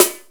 JAMAICAN SD.wav